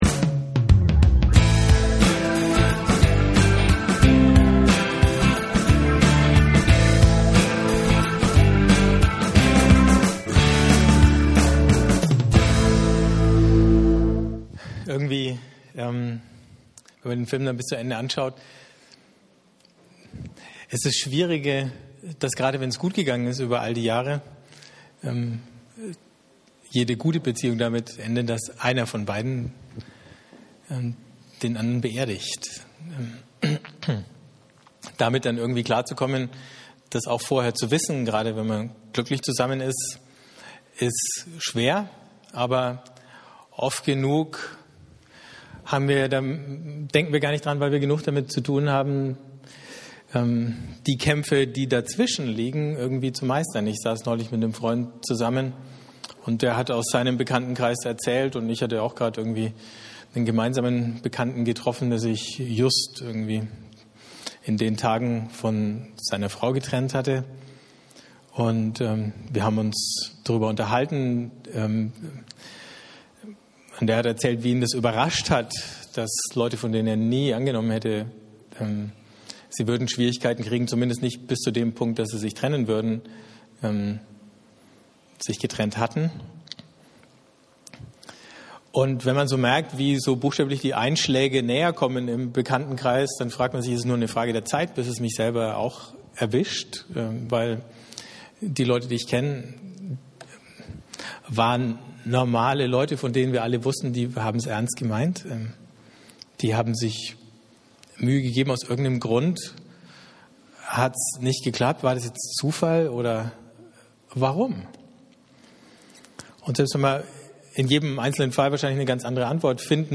Wer den Vortrag inzwischen schon mal hören möchte, während ich nach einer Lösung suche, kann das hier tun.